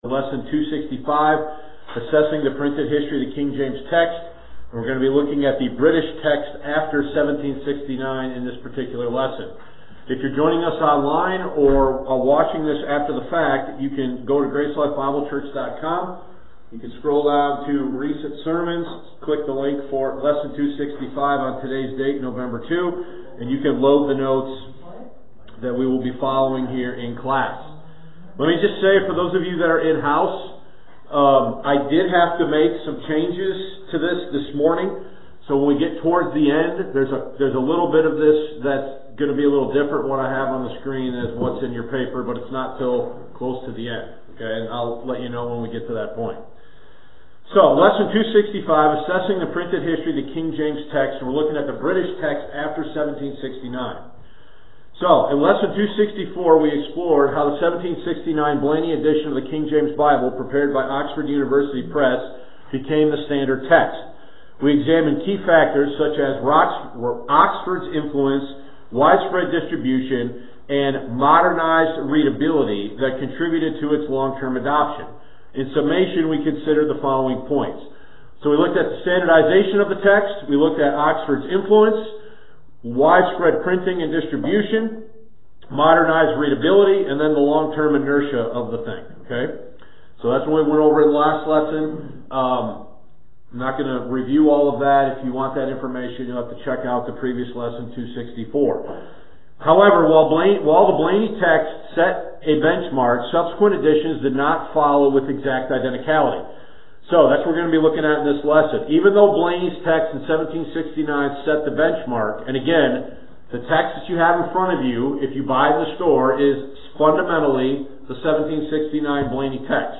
Lesson 265 Assessing the Printed History of the King James Text (British Text After 1769)